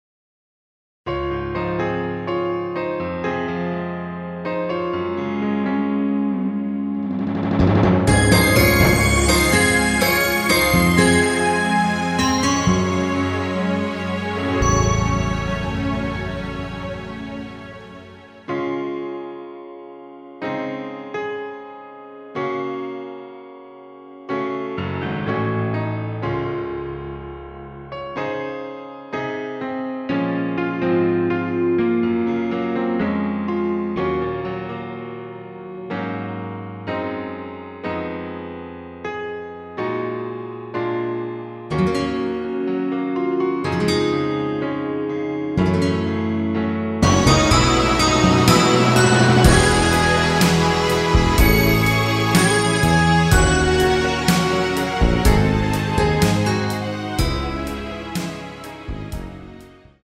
심플한 MR
Db
앞부분30초, 뒷부분30초씩 편집해서 올려 드리고 있습니다.
중간에 음이 끈어지고 다시 나오는 이유는